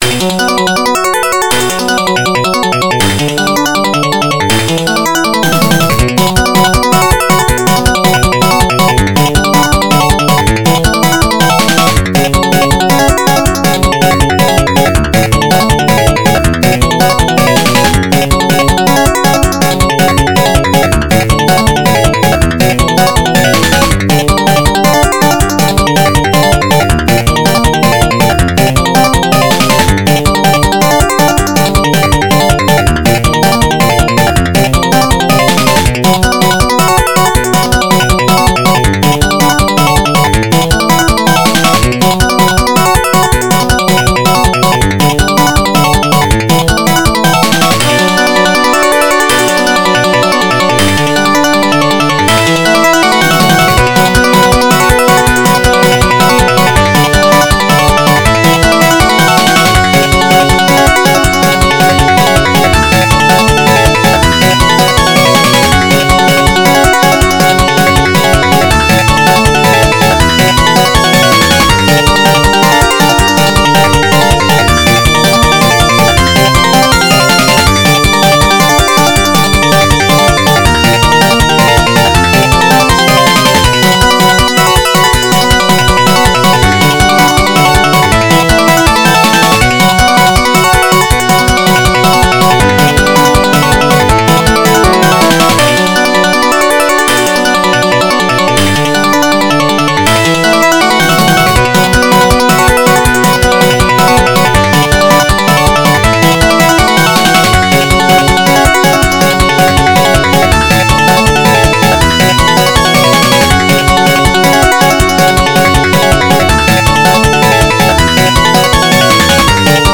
原游戏FM版，由PMDPlay导出。